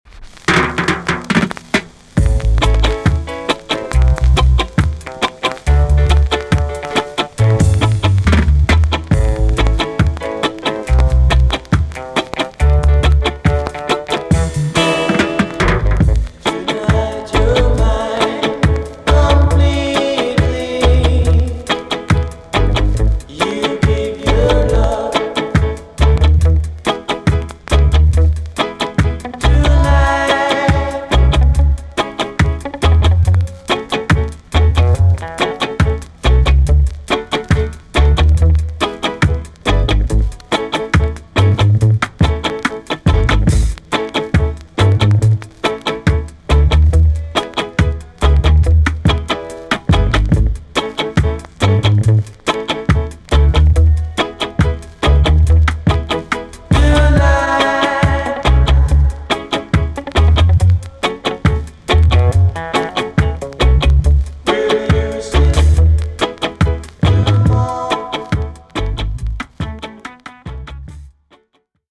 B.SIDE Version
VG+ 軽いチリノイズがあります。